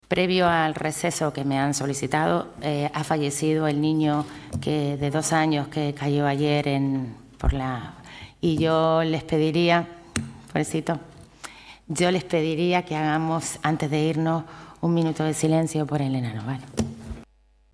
El Pleno del Ayuntamiento de la capital guarda un minuto de silencio en su memoria
minuto_silencio_muerte_menor_arrecife.mp3